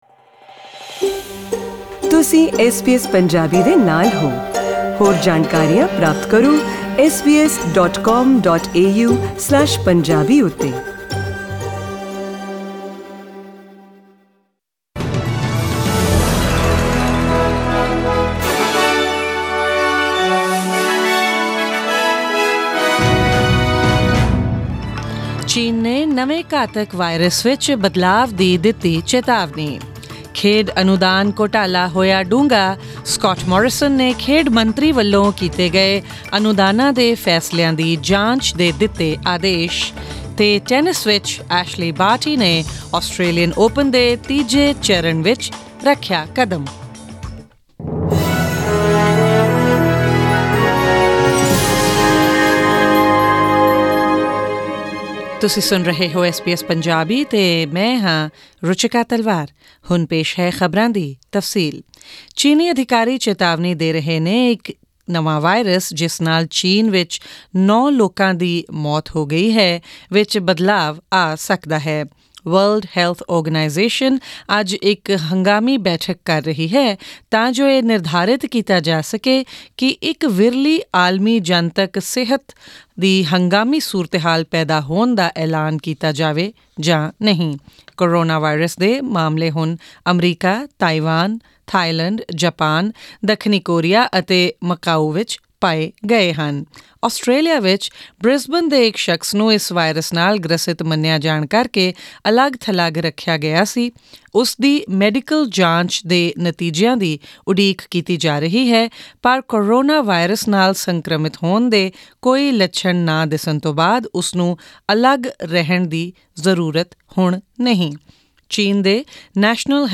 Australian News in Punjabi: 22 January 2020